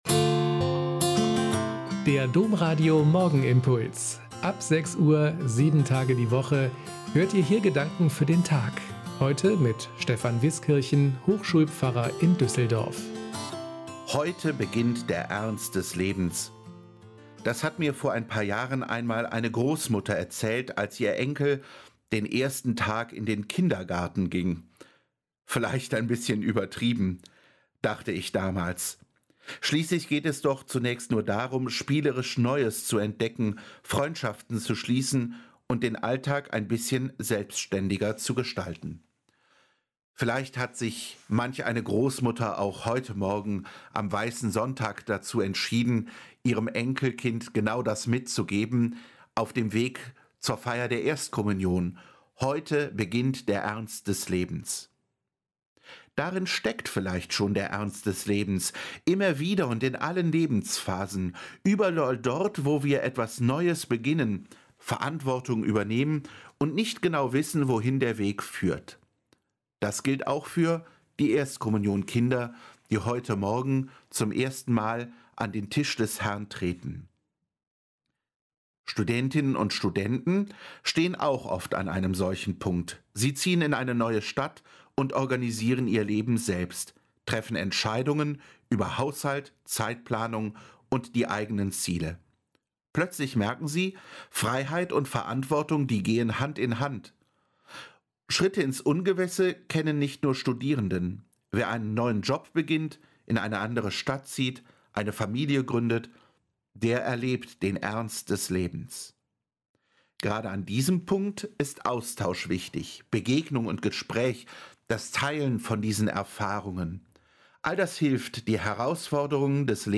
Morgenimpuls